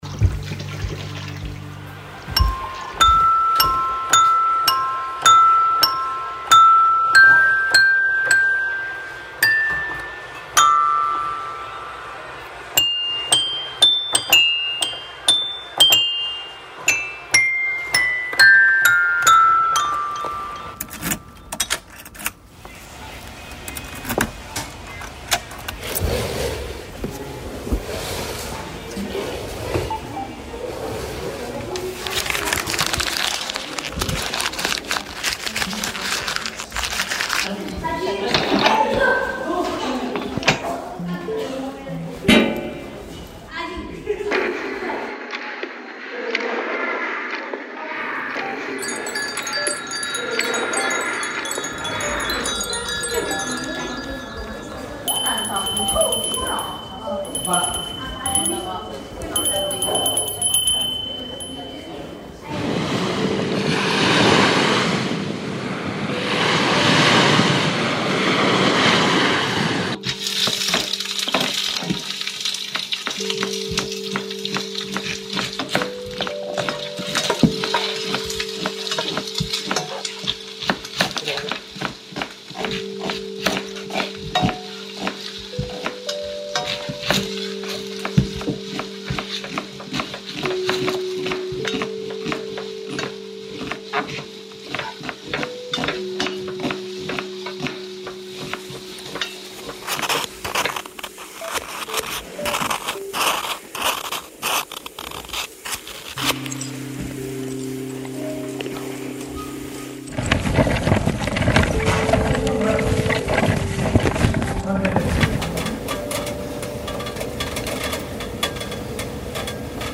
KIITO_300_BGM_mst1.mp3